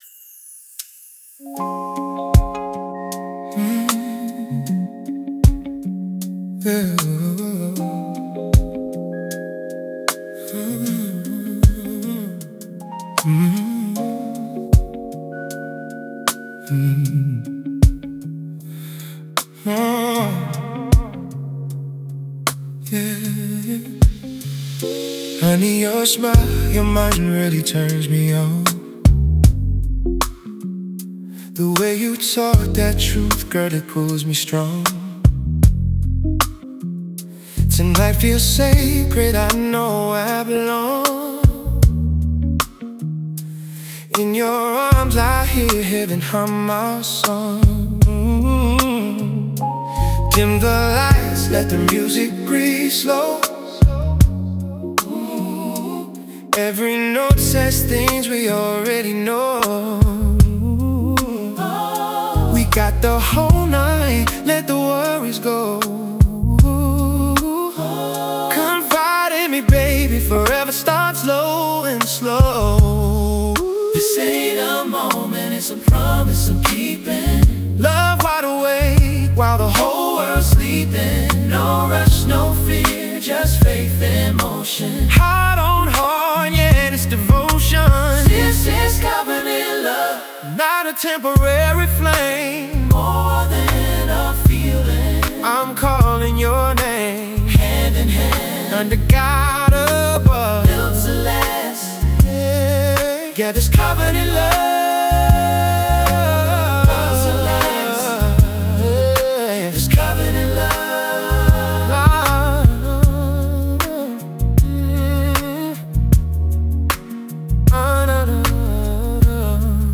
• Gospel foundation with classic R&B warmth